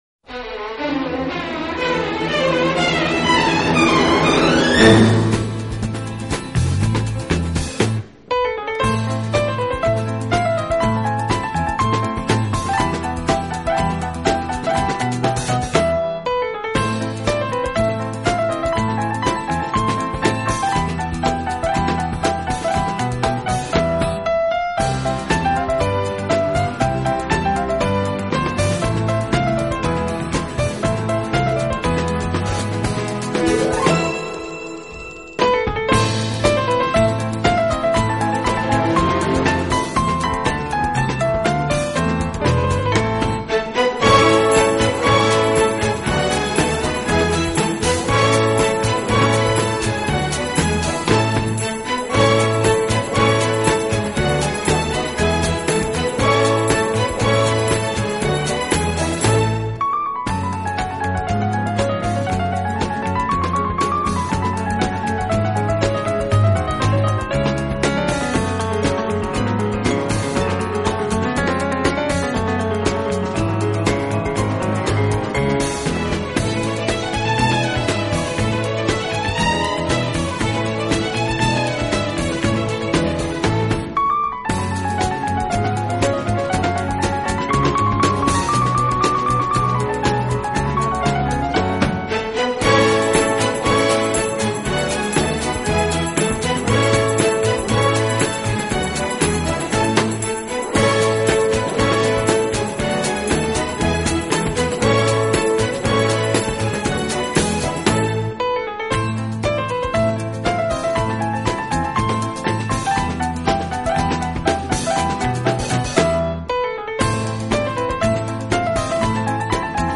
除此之外，他在选择乐队伴奏时也非常考究，常使用弦乐来